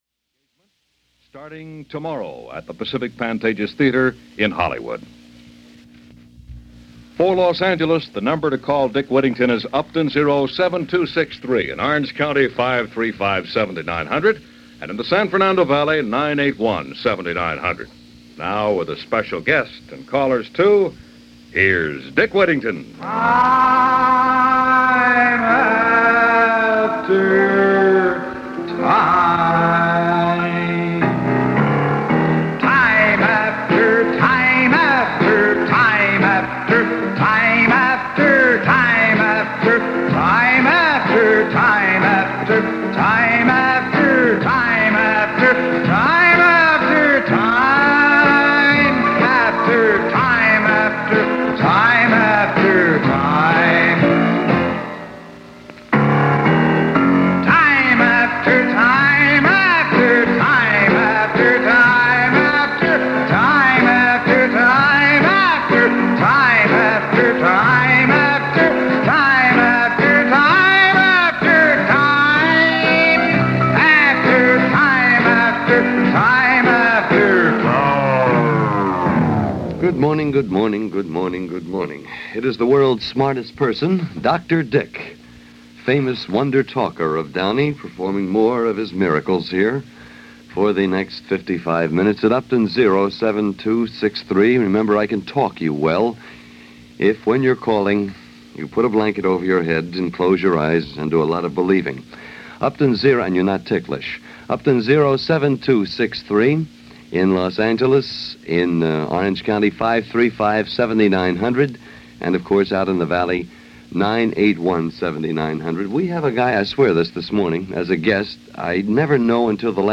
But in the 1960s, the closest that came was Talk Radio; one person talking to another and everyone within the sound of those two voices could eavesdrop.
Suddenly, radio station switchboards were greeted with a Tsunami of people talking about everything from bunions to abortions.